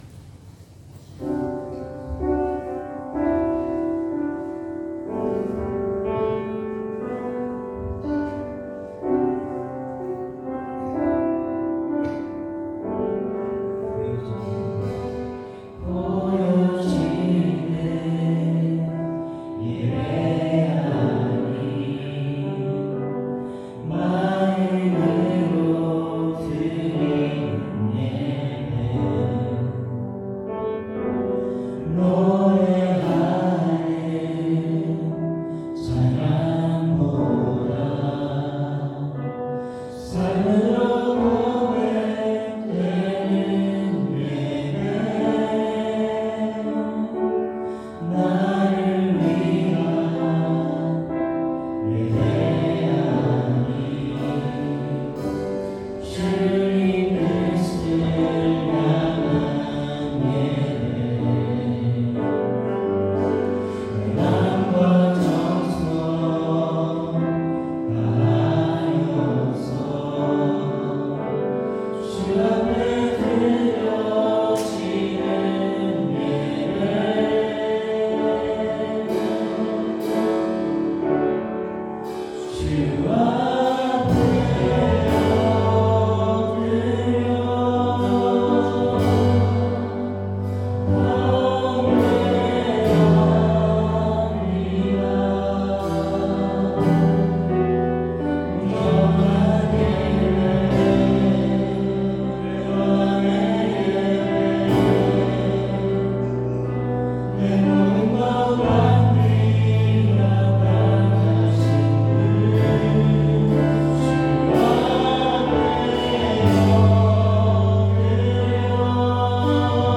2026년 02월 08일 주일찬양